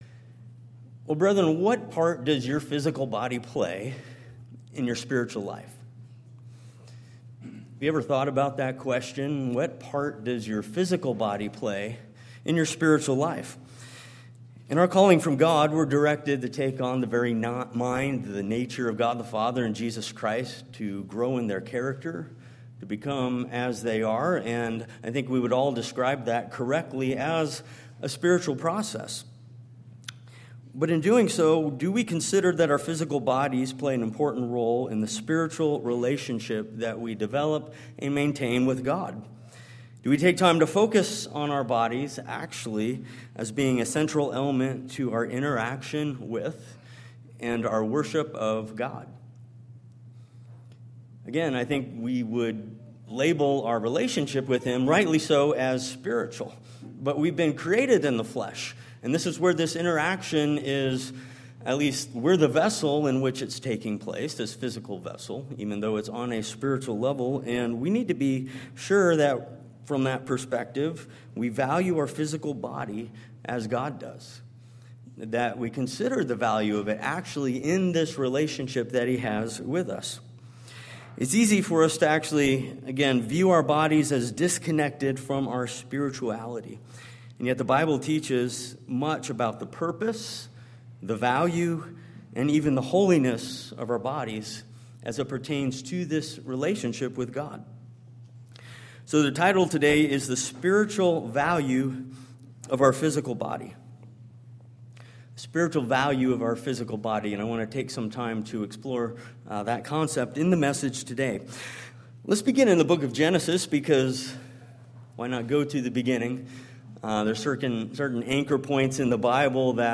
This sermon explores four ways in which the purpose of our physical body relates to the divine plan of God.